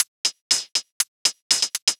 UHH_ElectroHatC_120-04.wav